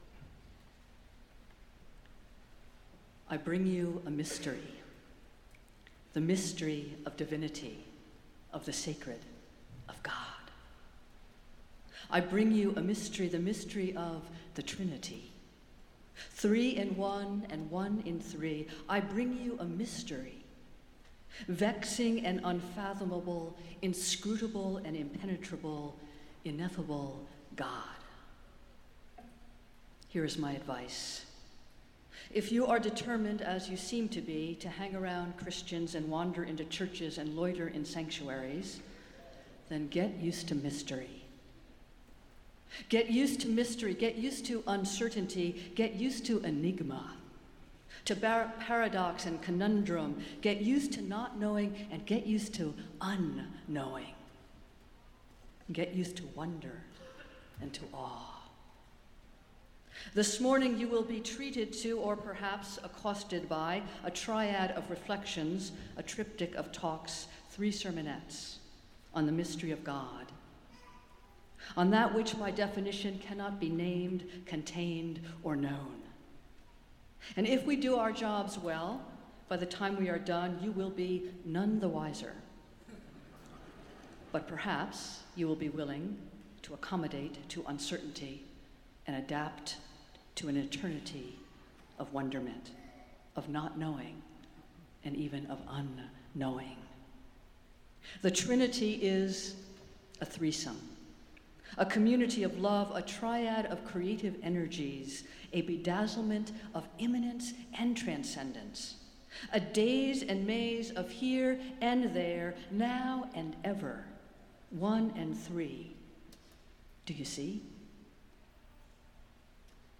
Festival Worship - Trinity Sunday